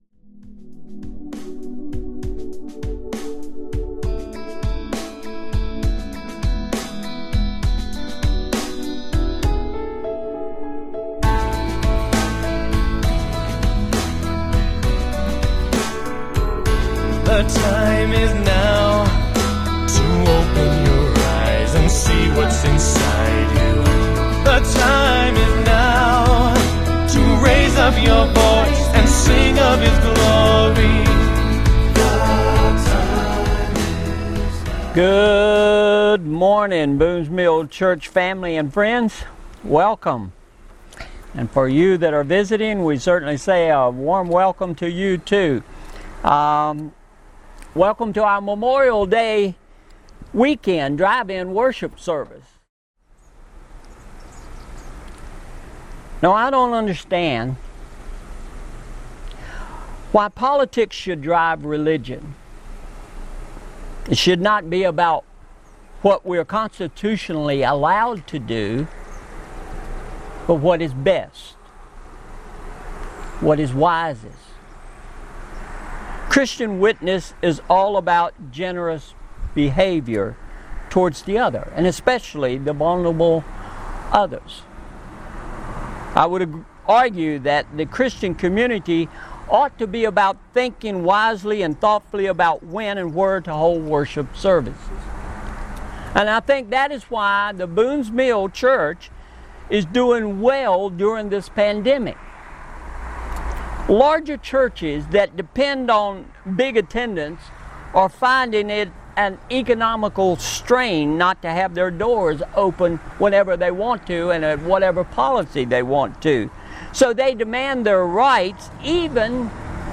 MP3 Notes Discussion Sermons in this Series Sermon Series Lord, We Need A Miracle – Part 5 "Before We Come Up Empty!"